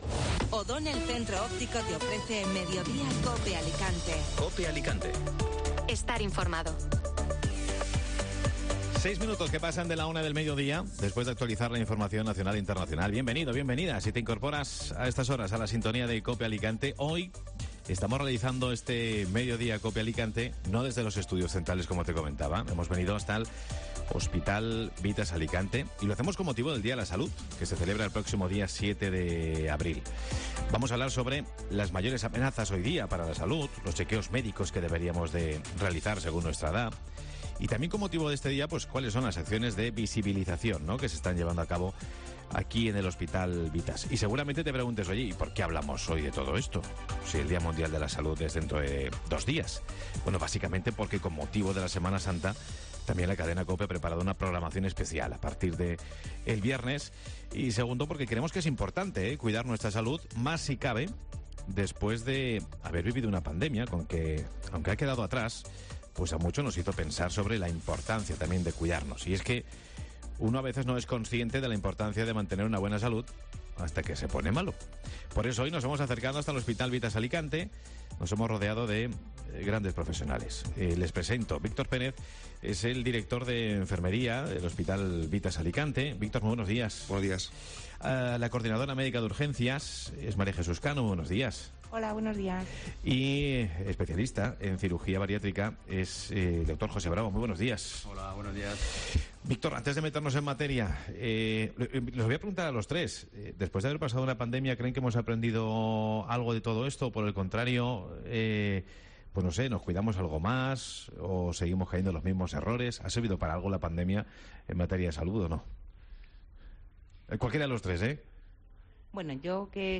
Mediodía COPE Alicante realiza el programa desde el hospital Vithas Alicante